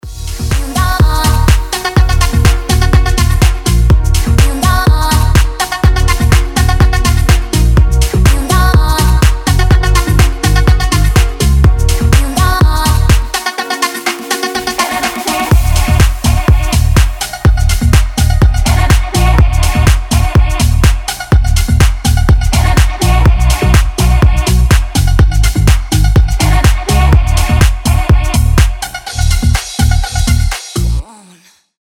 • Качество: 320, Stereo
ритмичные
заводные
house